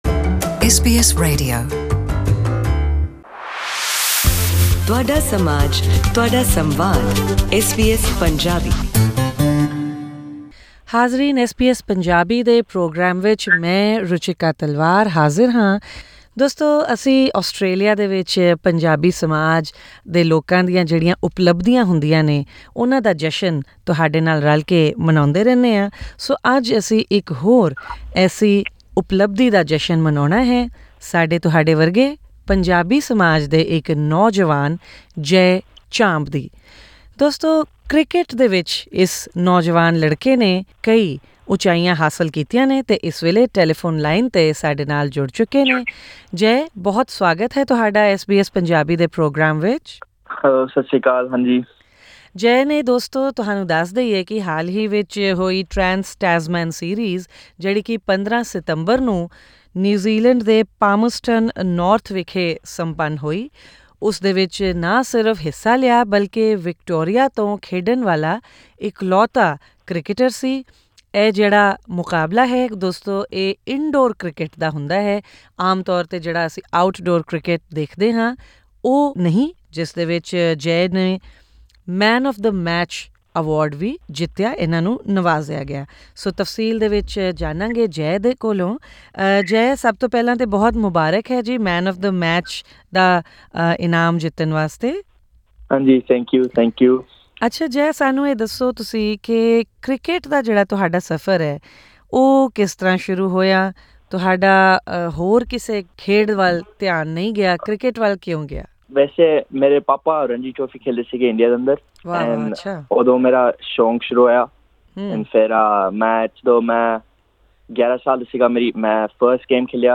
To hear his interview with SBS Punjabi, click on the audio link above.